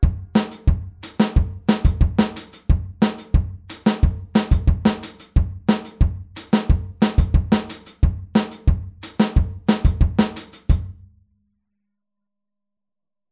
Nach dem 4tel-Offbeat setzen wir ein Echo hinzu
Hier spielen wir den Offbeat mit der rechten Hand wieder auf dem HiHat.